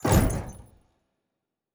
Special Click 20.wav